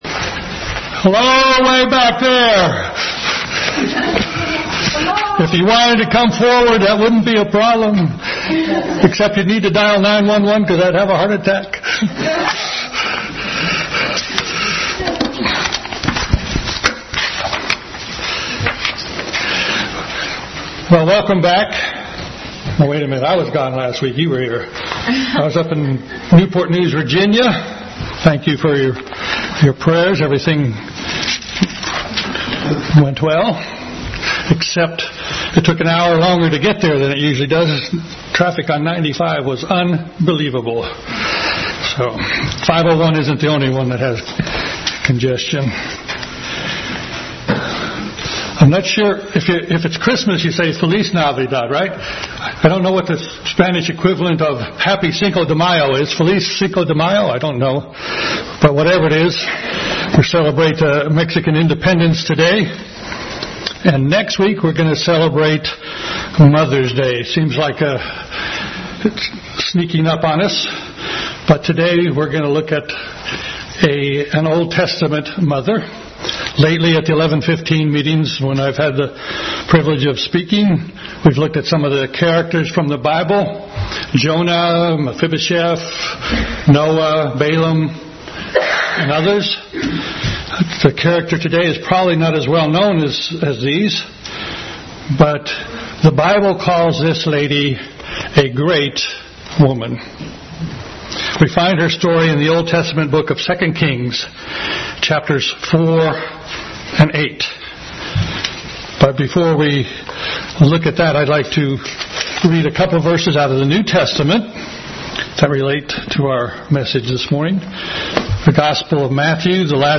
Bible Text: 2 Kings 4:8-37, 2 Kings 8:1-6, Matthew 10:40-42, Matthew 25:40, Hebrews 11:1-3, 35 | Family Bible Hour message.